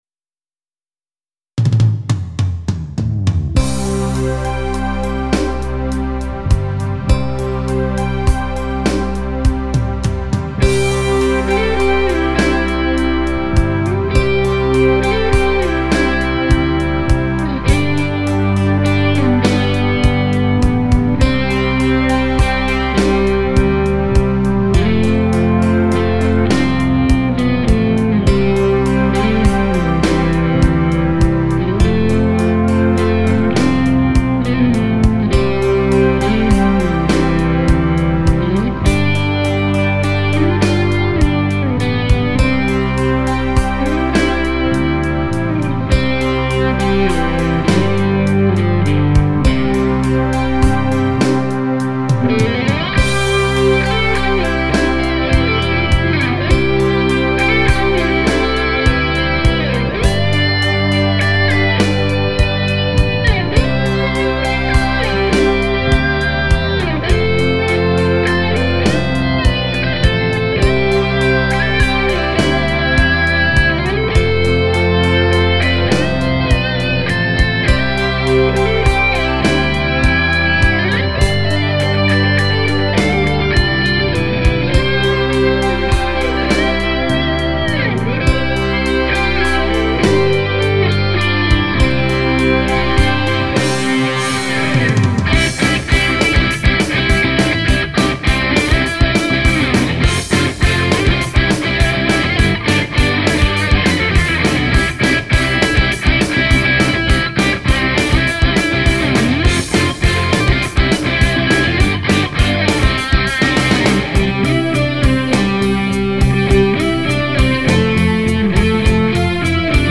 Instrumental
After being too lazy in 2000 and not having my equipment with me in Australia in 2001, I finally managed to get off my butt to record another Christmas Medley just as in 1999. This is a guitar medley of the well-known Christmas songs “Silent Night, Holy Night” and “Jingle Bells”.